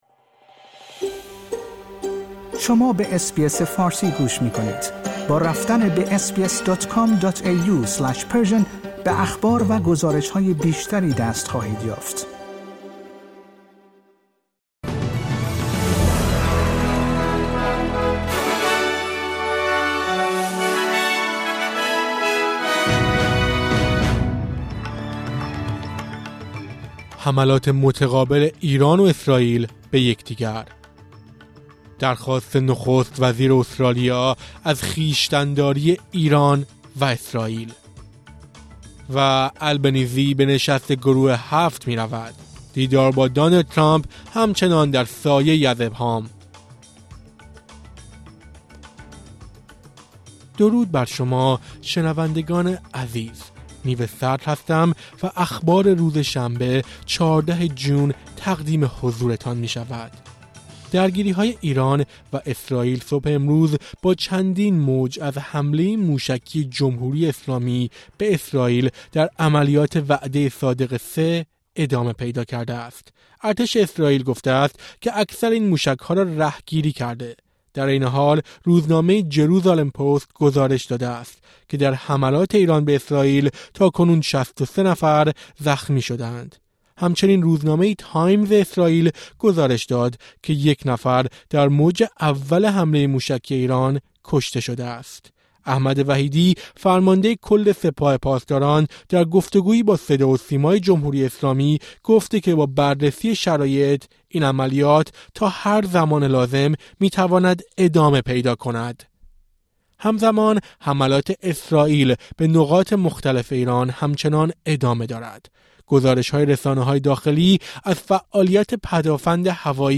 در این پادکست خبری مهمترین اخبار امروز شنبه ۱۲ جون ارائه شده است.